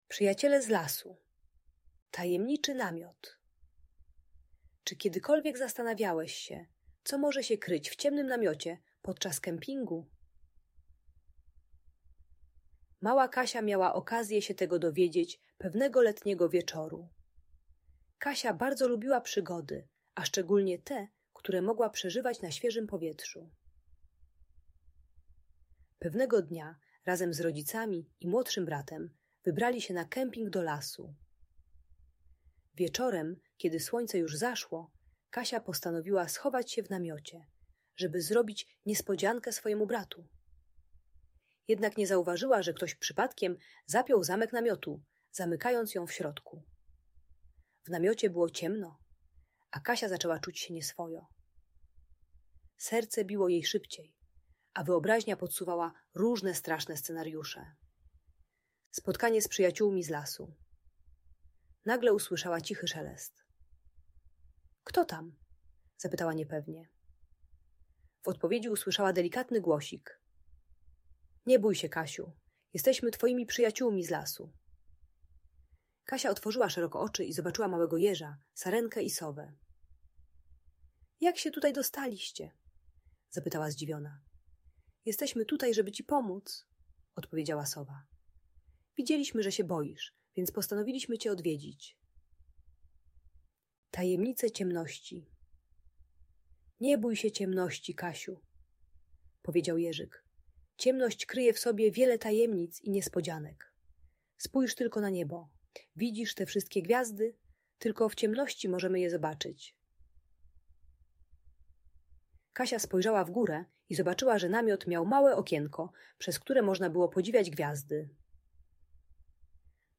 Tajemniczy Namiot - Audiobajka